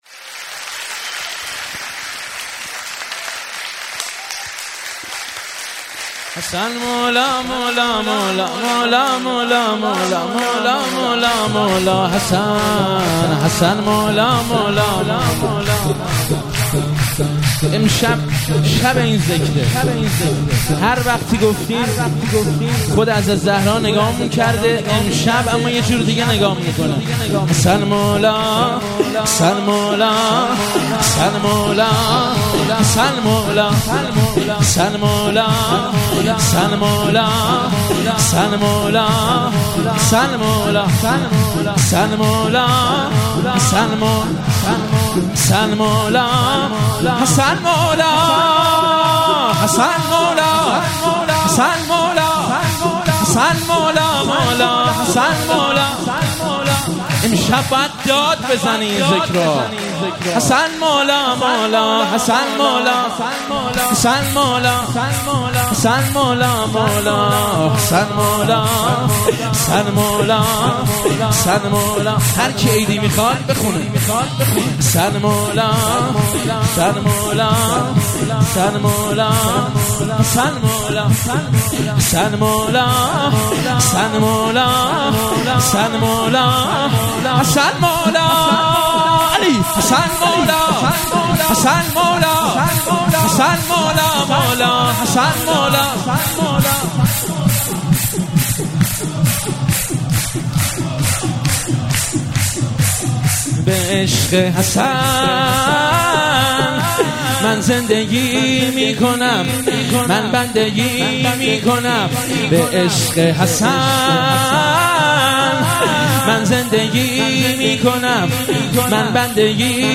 مراسم مناجات خوانی شب پانزدهم و جشن ولادت امام حسن مجتبی علیه السلام ماه رمضان 1444